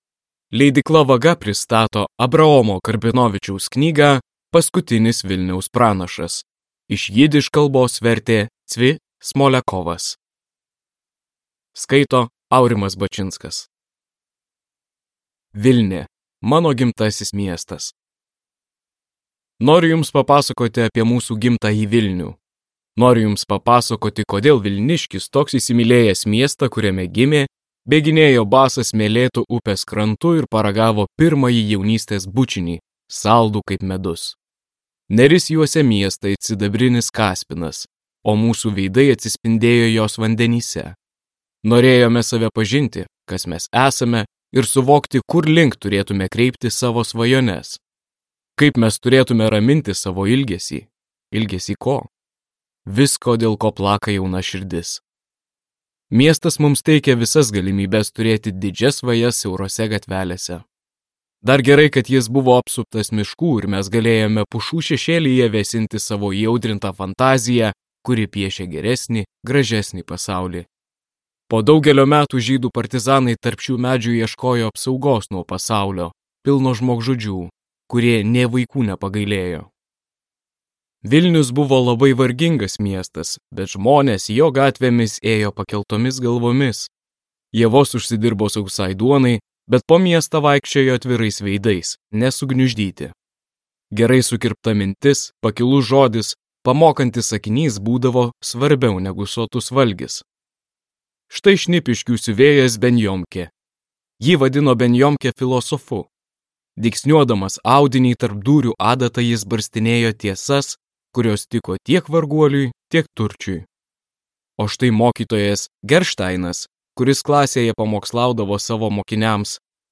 Paskutinis Vilniaus pranašas | Audioknygos | baltos lankos